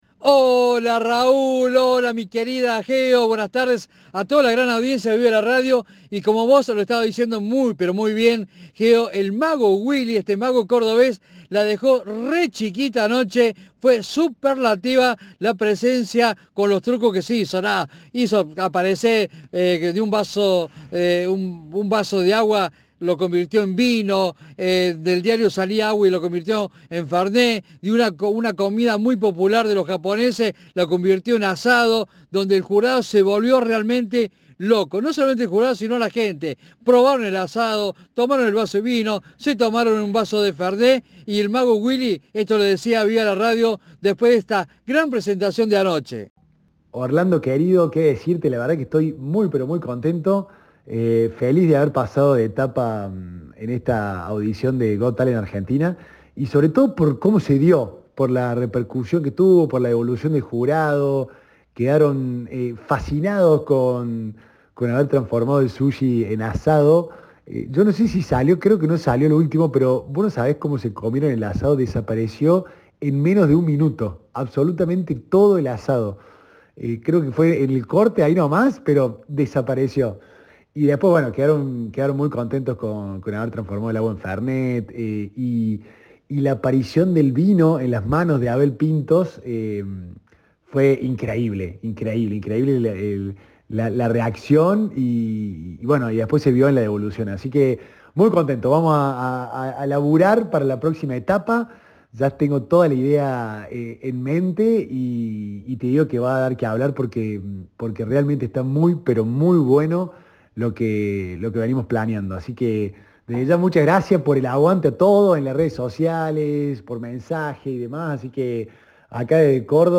En diálogo con Cadena 3, el artista se mostró feliz por avanzar a la siguiente etapa y por la repercusión que tuvo su paso por uno de los programas más populares del país.